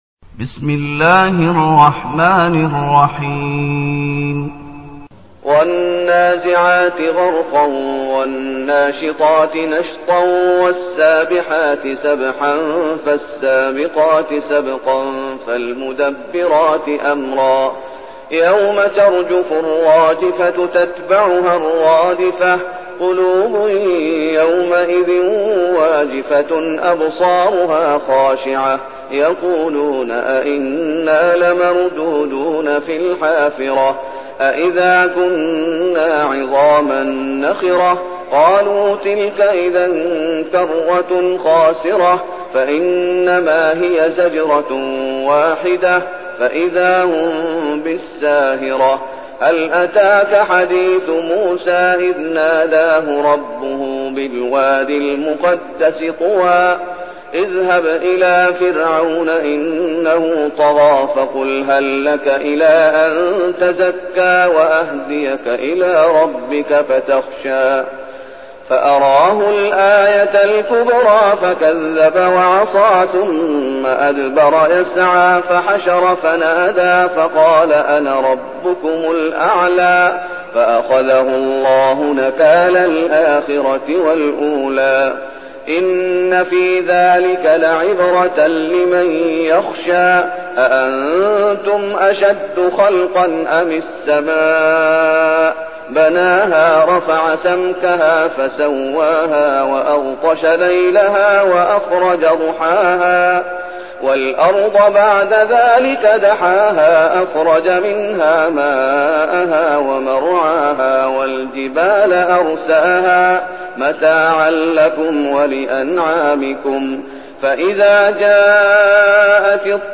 المكان: المسجد النبوي الشيخ: فضيلة الشيخ محمد أيوب فضيلة الشيخ محمد أيوب النازعات